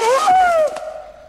• SURPRISED SCREAM ECHO.wav
SURPRISED_SCREAM_ECHO_3g4.wav